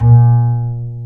Index of /90_sSampleCDs/Roland L-CDX-01/BS _Jazz Bass/BS _Ac.Fretless